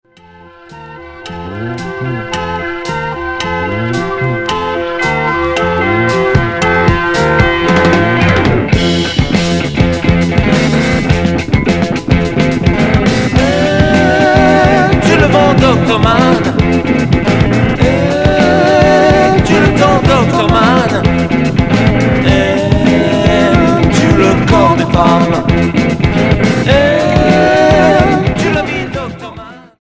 Progressif Deuxième 45t retour à l'accueil